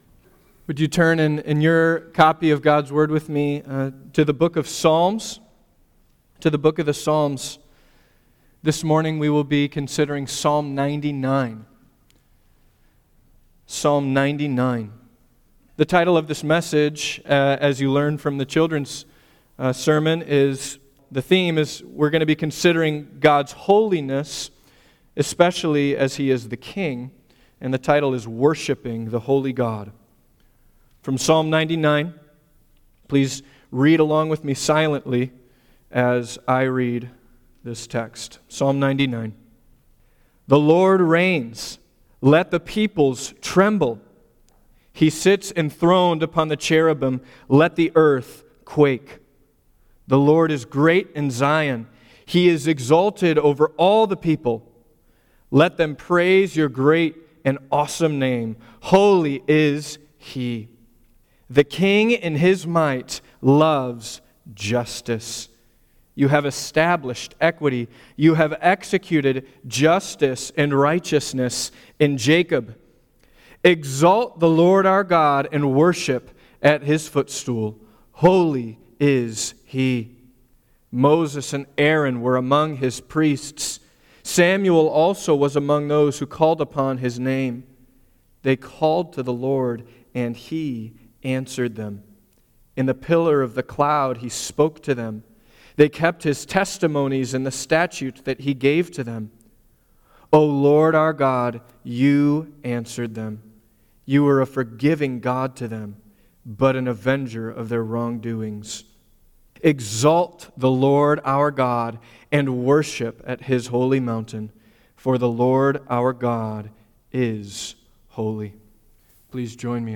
Worship Series Various Sermons Book Psalms Watch Listen Save In Psalm 99:1-9, the author calls us to turn from idols to worship the one true God, the holy King.